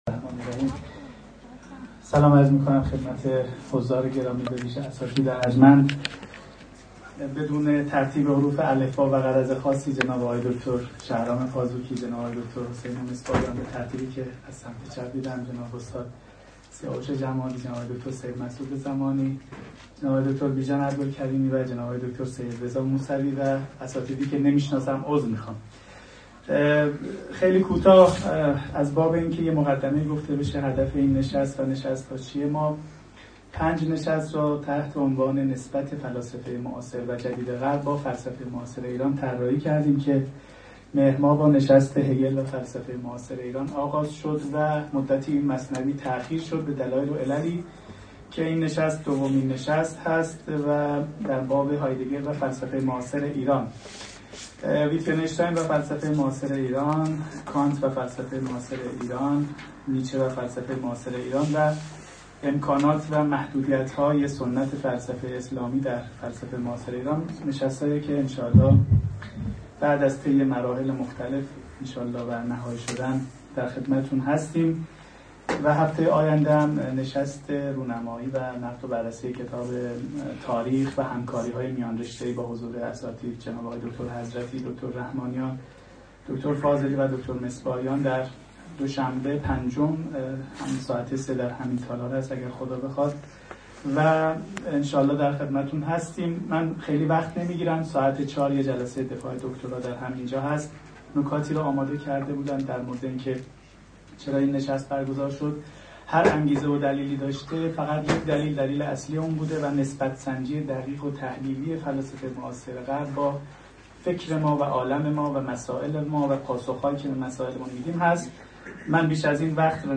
سخنرانی
در دانشگاه تهران به همت جهاد دانشگاهی، آذرماه ۹۲ ایراد شده است.